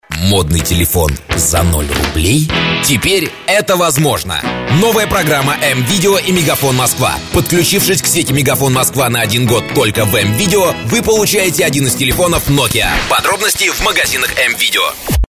Реклама акции "Телефон за 0 рублей"